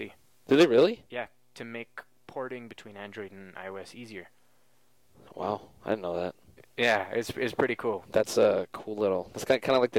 Functions of Bookended Narrow-Pitch-Range Regions
3. Grudging appreciation